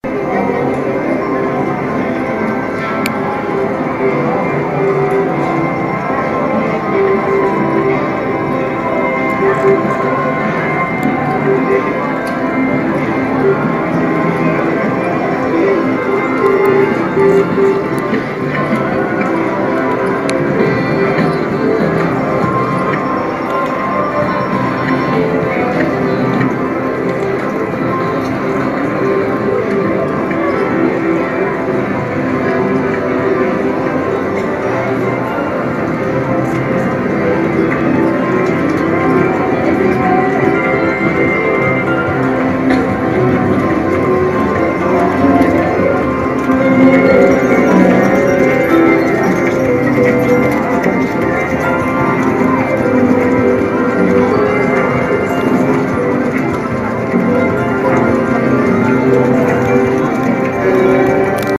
Living Minute - Casino Slot Machine Room
background environment field-recording industrial interior natural surrounding sound effect free sound royalty free Sound Effects